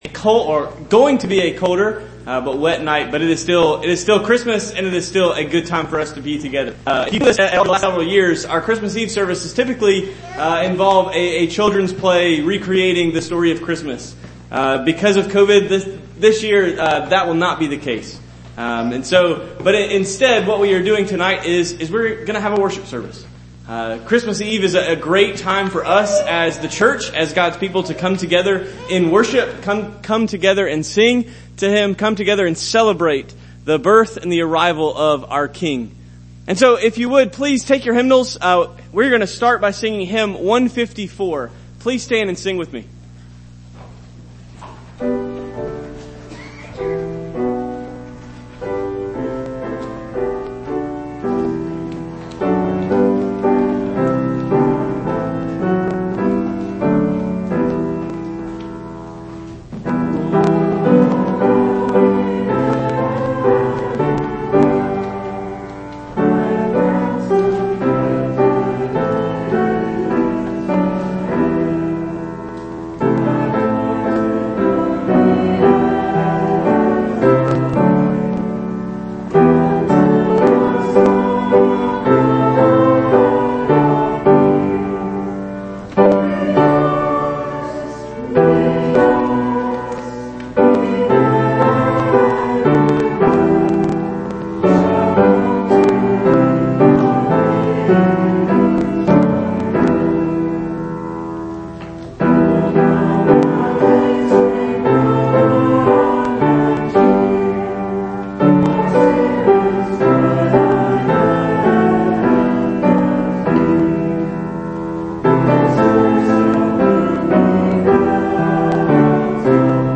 December 24 2020 – Christmas Eve Service – Bethel Bear Creek Reformed Church: Media